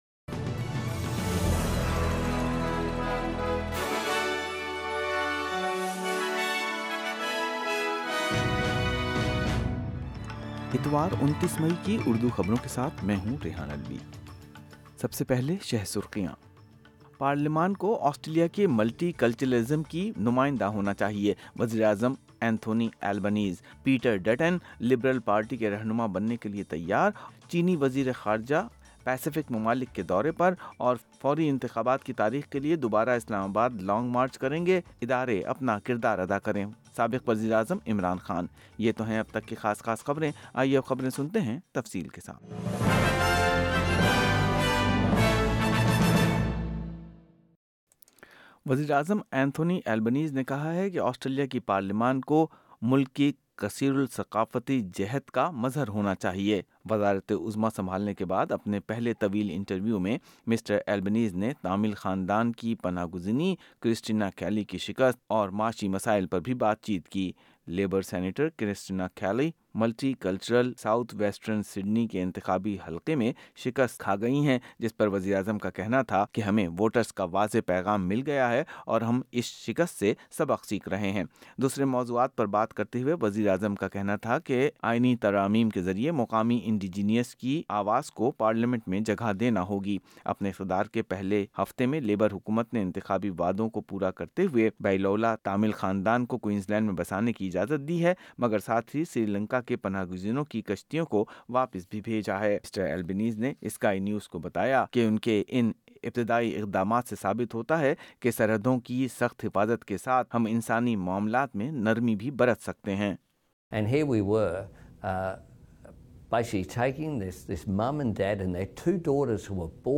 Labor set to find out whether they have the numbers to govern in majority-Urdu News 29 May 2022